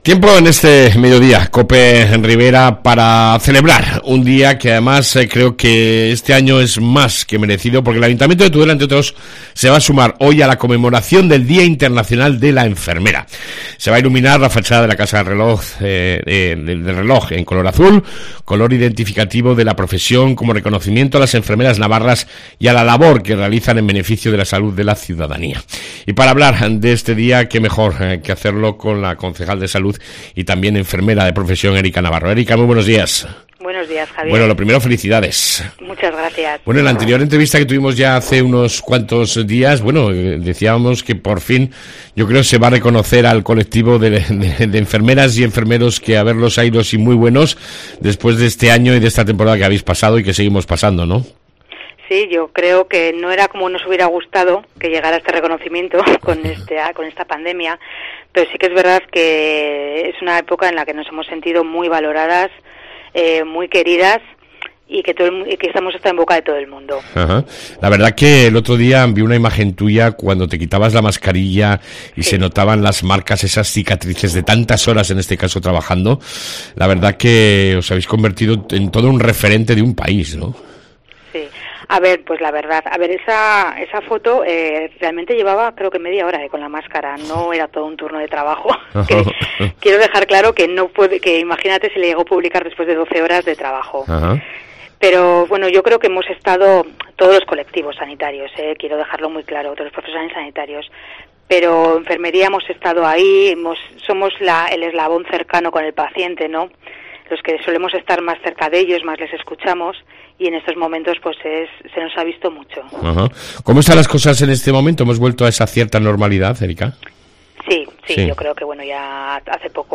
AUDIO: Entrevista con la Concejal y enfermera Erika Navarro